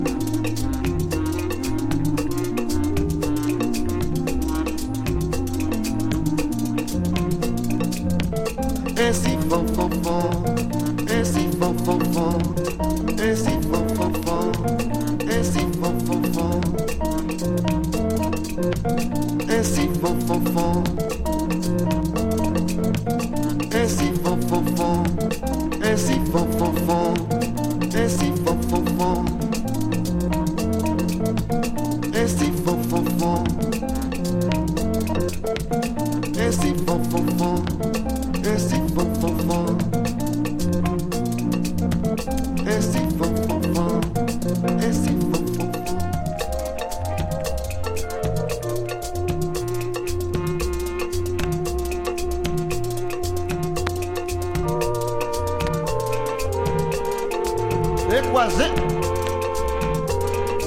エレクトーンビートと思われる隙間だらけトラックが絶妙。中南米ラテンヴォーカルも癖になります。